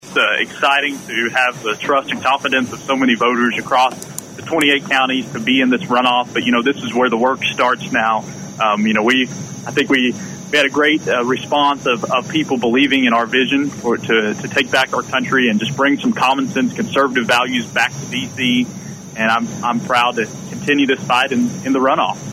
Frix called into Bartlesville radio on election night to say t